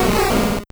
Cri de Pomdepik dans Pokémon Or et Argent.